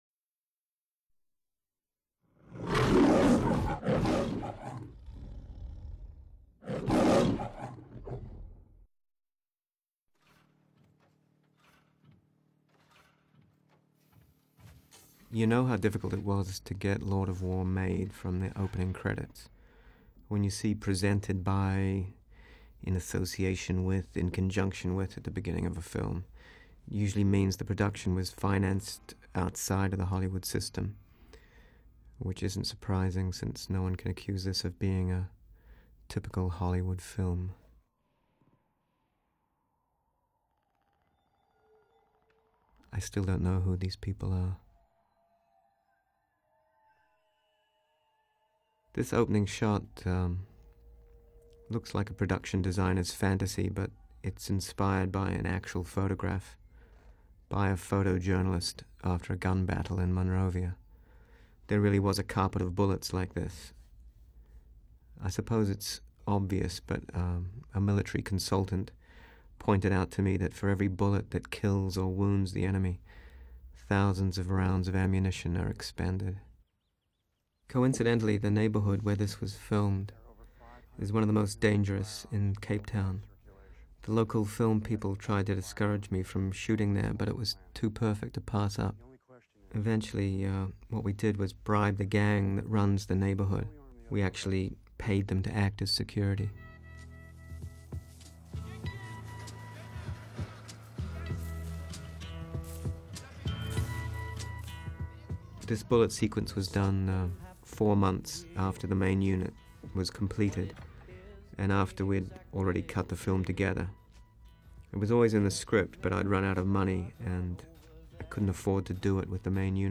Commentary track by Director Andrew Niccol.aac